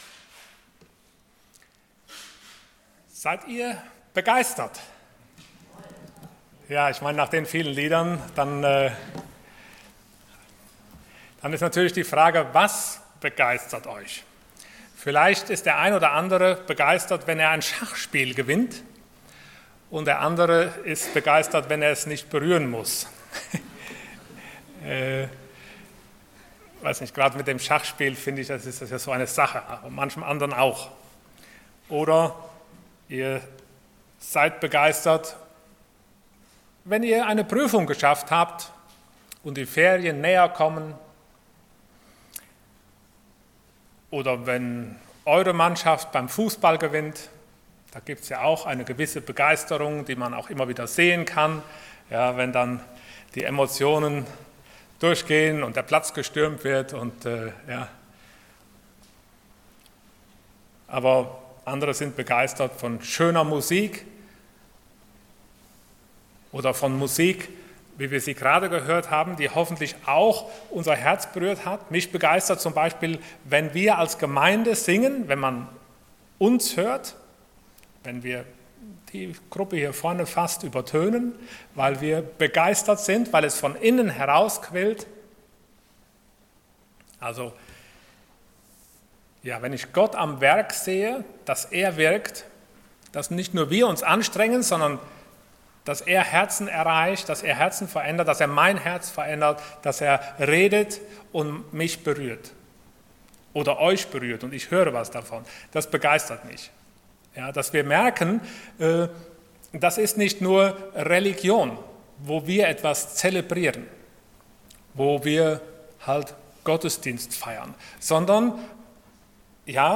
Passage: Acts 2:1-37 Dienstart: Sonntag Morgen